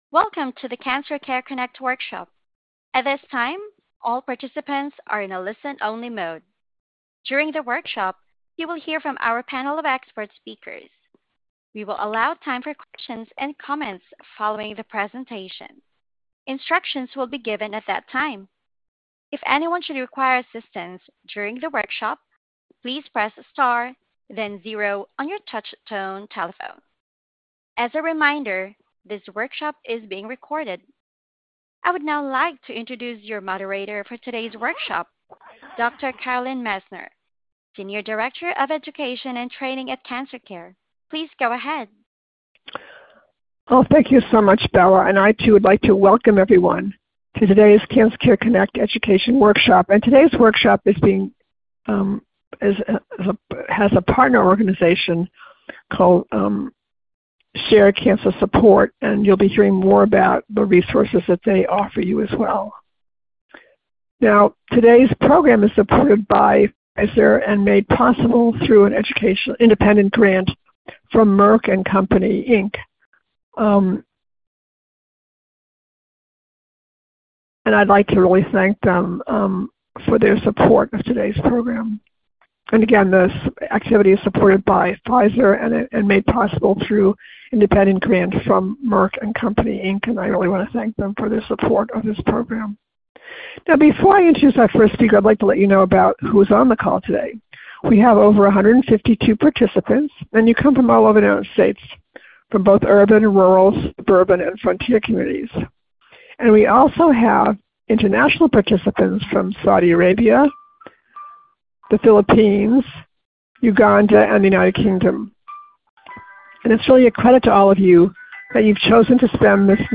Questions for Our Panel of Experts
This workshop was originally recorded on February 19, 2025.